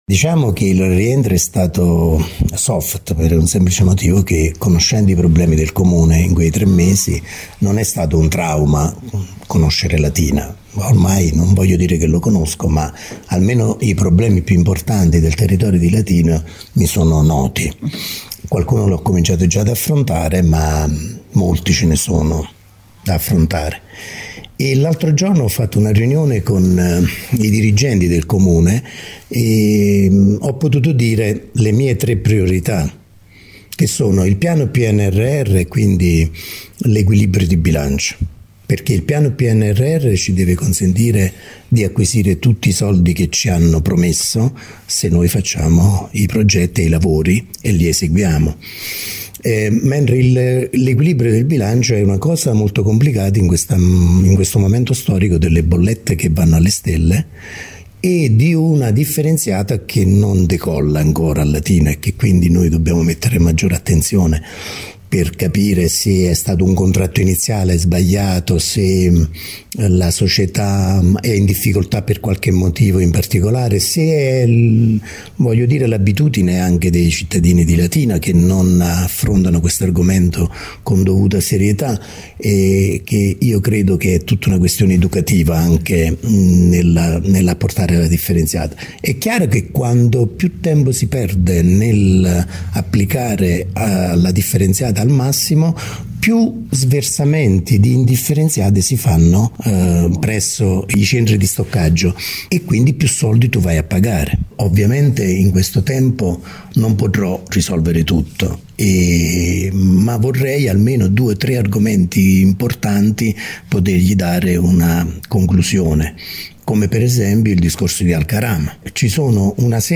Lo ha detto questa mattina il commissario straordinario di Latina Carmine Valente raggiunto nel suo studio in Piazza del Popolo dove si è insediato all’indomani della sfiducia.